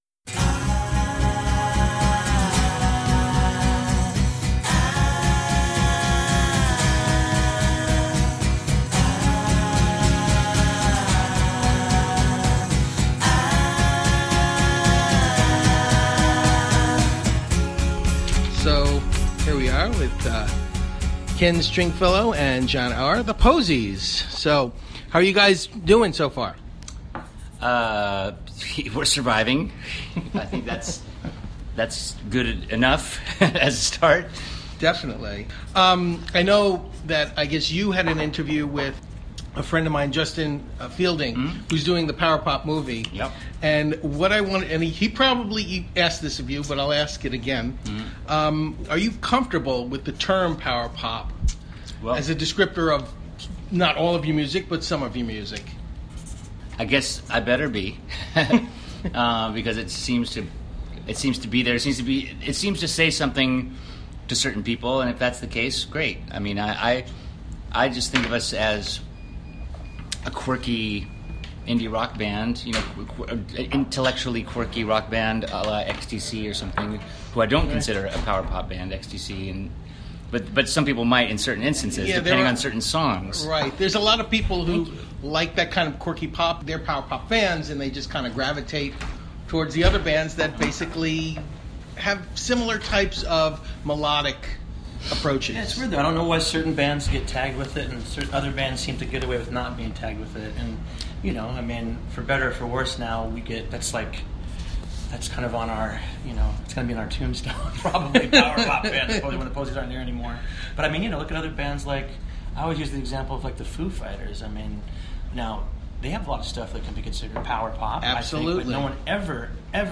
Ken Stringfellow and Jon Auer joined with me before the show to talk about their remastered albums coming out on Omnivore Records this spring, Big Star and Alex Chilton, plus how cool it was to have Ringo sing one of your songs. We had a great casual conversation (14 minutes)
The_Posies_Interview_2-25-18_ld.mp3